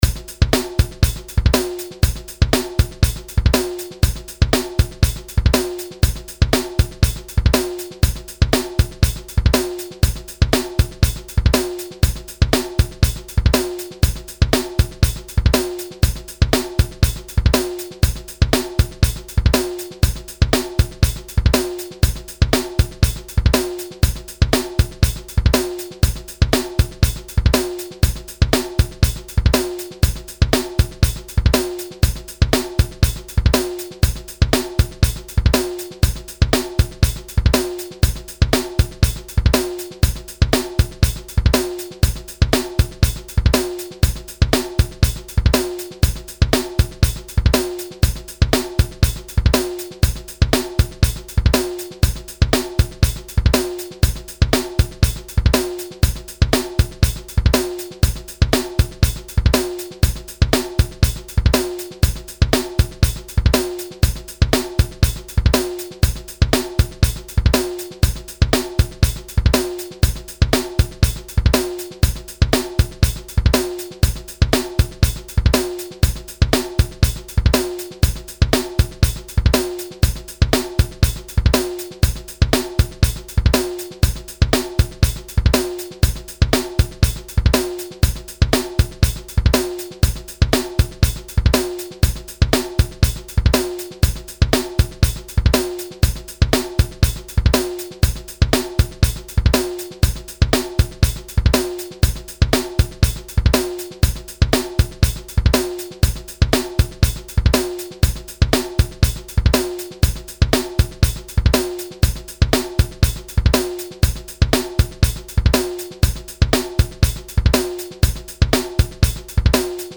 rock music backround.mp3